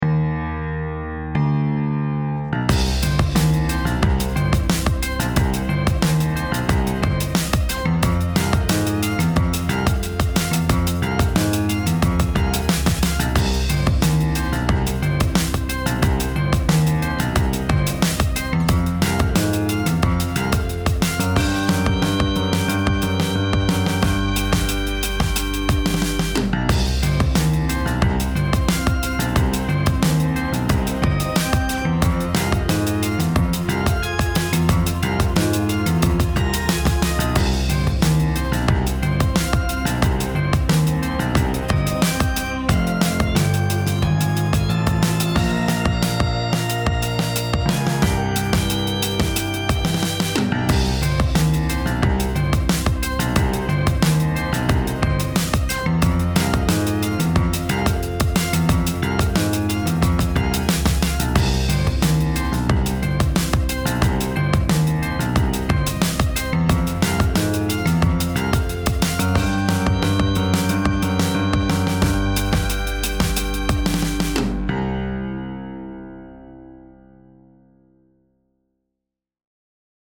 holiday song with dark vibes
A tune of fright and delight
90 BPM
holiday dark piano synthesizer drums organ